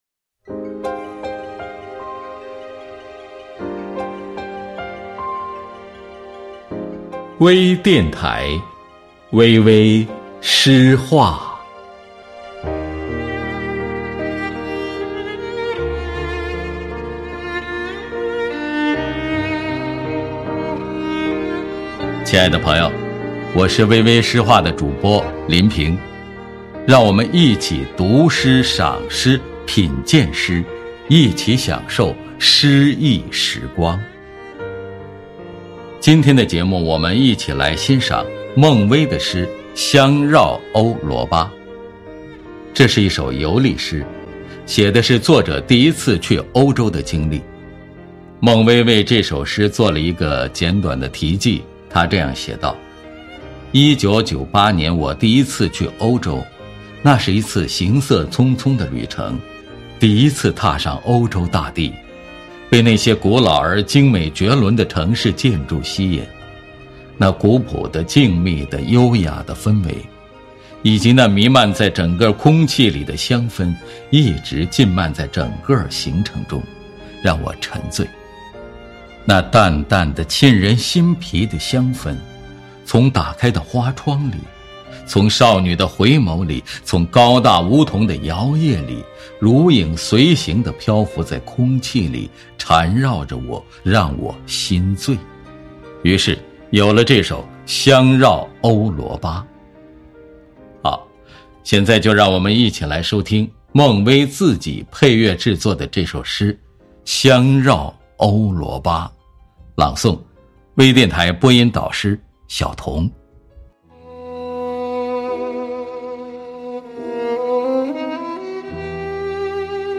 多彩美文  专业诵读
朗 诵 者